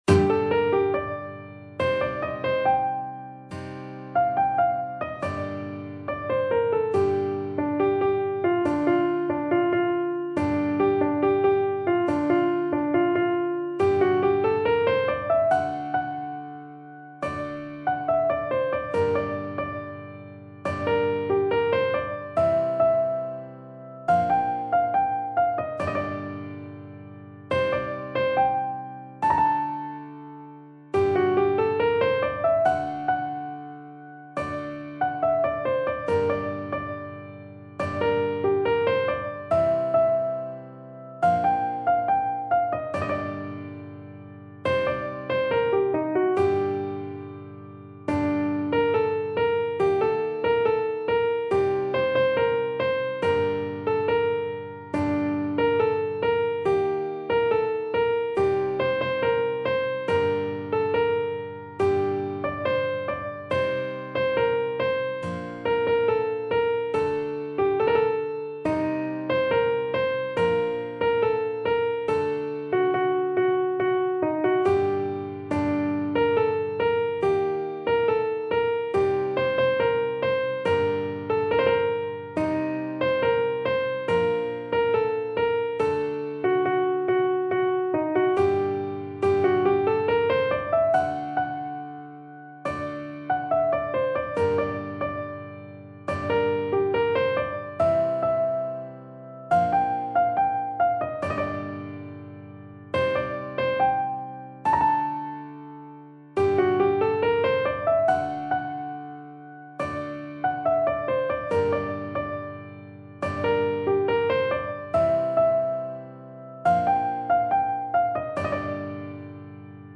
به همراه آکورد های مربوطه